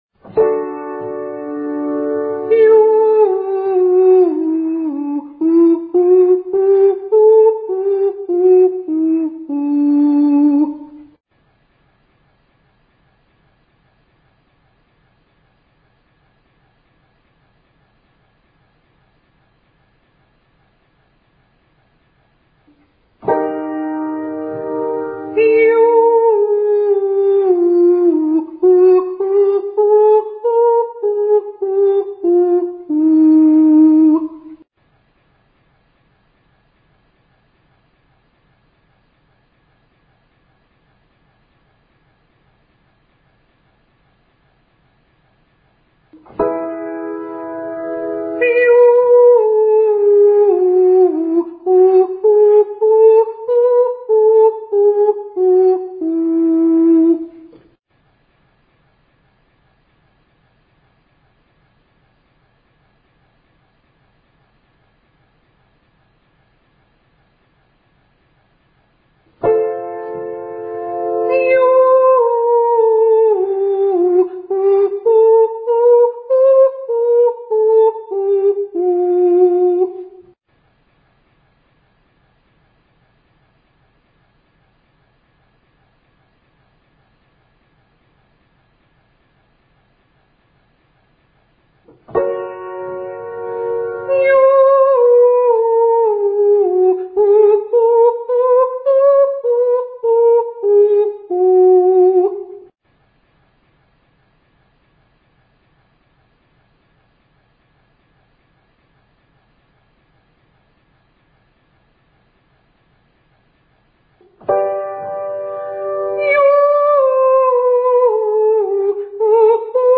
1131 Legato staccato Yoo In Tempo Ascending
Vft-1131-Legato-Staccato-Yoo-In-Tempo-Ascending.mp3